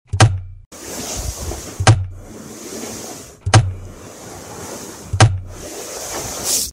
Perfectly Satisfying Wood Block Drop sound effects free download
Perfectly Satisfying Wood Block Drop & Seamless Loop.